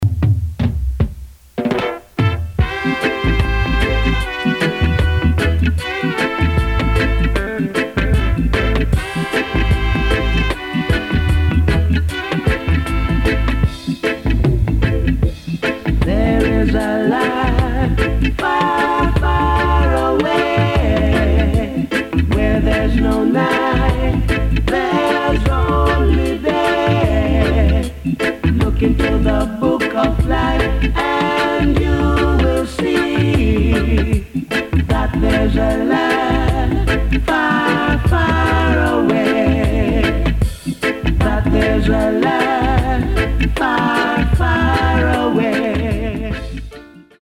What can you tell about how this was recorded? Recorded: Joe Gibbs 'N' Harry J. Studios Kgn. Ja.